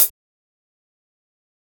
nightcore-hat.wav